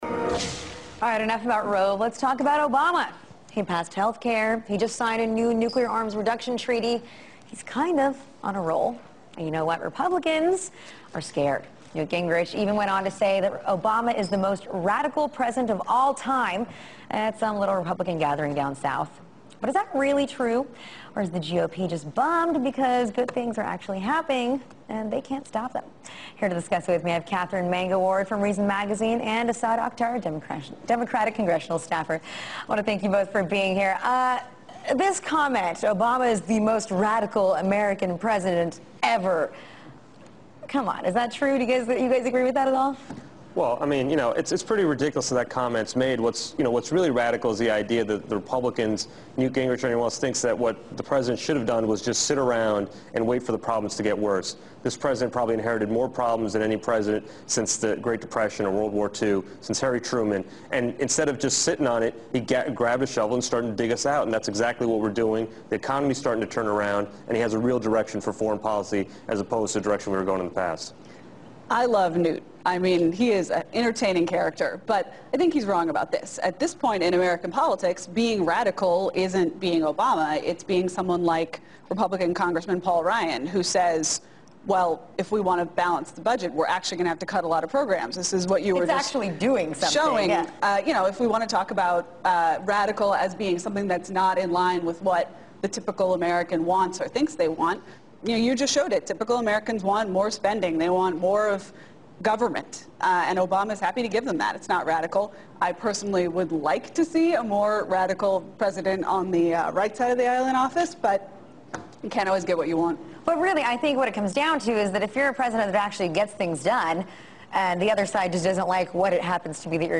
as part of a panel to discuss whether President Barack Obama is a radical and other political issues of the week